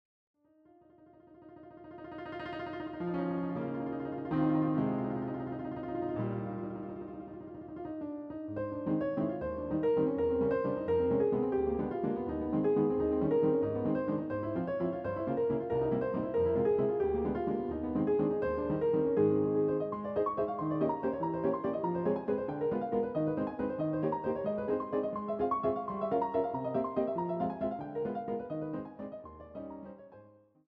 A quality audio recording of an original piano roll